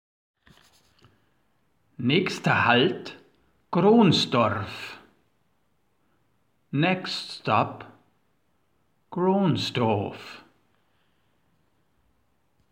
Mehr noch, sie wurden modernisiert, um die S-Bahn München als modernes Verkehrsunternehmen zu präsentieren: Sie erfolgen nun durch einen English Native Speaker und, um die mitfahrenden Münchner nicht zu diskriminieren, auf deutsch mit bayrischem Akzent.
Ich stieg in Trudering in die S-Bahn ein, sie fuhr ab und bald danach kam die Ansage für den nächsten Halt:
Mit fiel auf, dass im Bayrischen das R nach Vokalen sehr betont wird, während es im Englischen kaum hörbar ist.